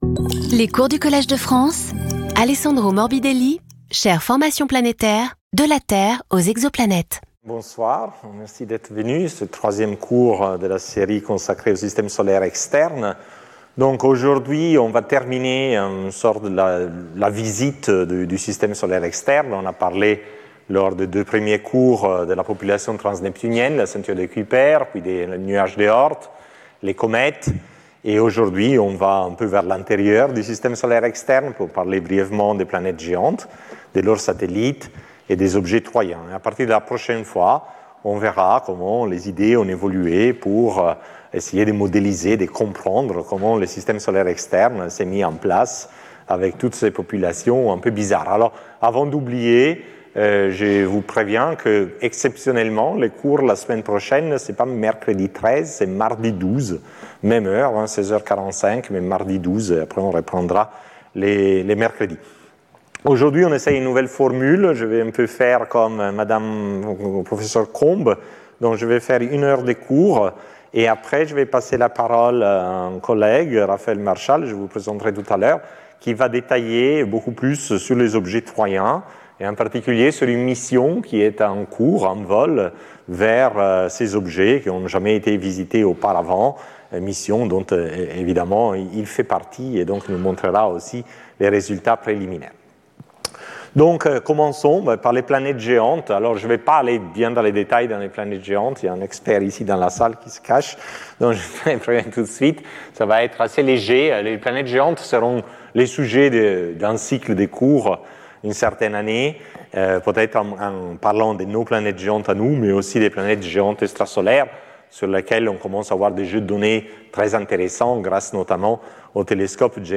Skip youtube video player Listen to audio Download audio Audio recording One-hour lecture , followed by the seminar. Abstract After a brief introduction to the properties of the giant planets, we'll describe their satellite populations, focusing on the so-called " irregular " satellites due to their eccentric and inclined orbits, probably captured from the protoplanetary disk. We will also examine the dynamical properties of Trojans, objects sharing the orbits of Jupiter or Neptune and oscillating around the equilibrium points discovered by J.-L. Lagrange.